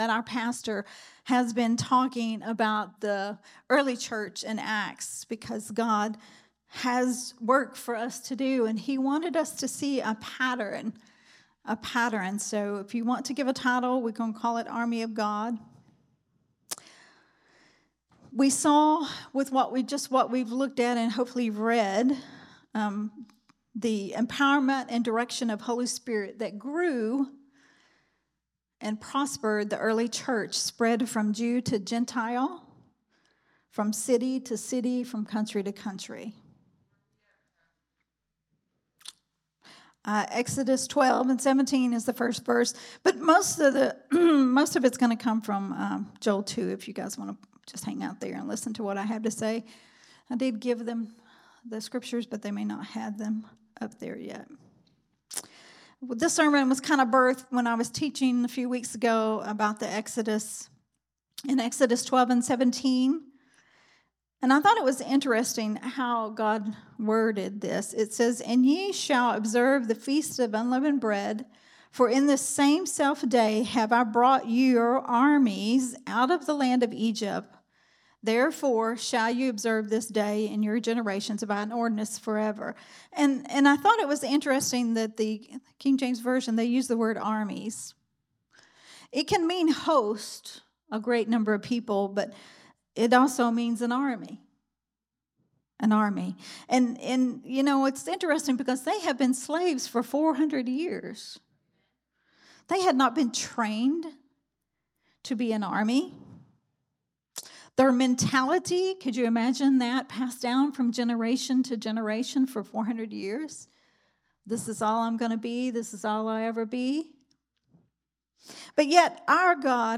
Sunday Morning Worship Service Growth Temple Ministries